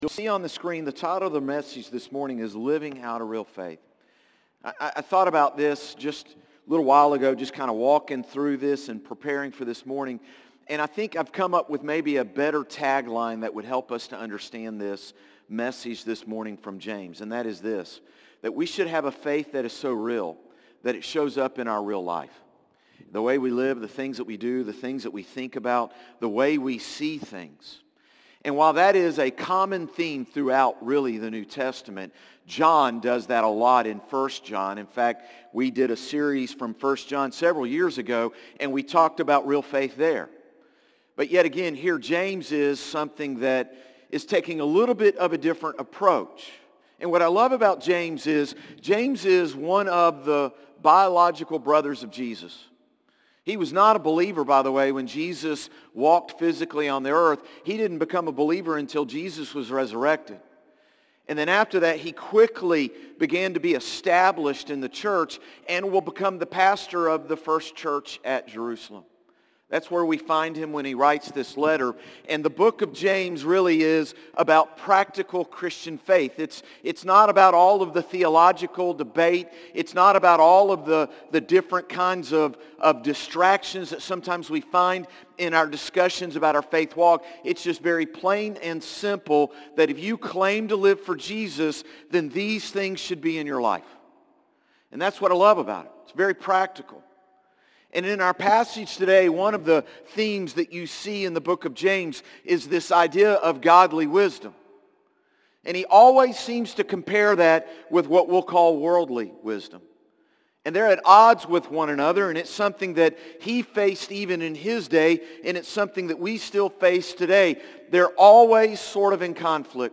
Sermons - Concord Baptist Church
Morning-Service-9-29-24.mp3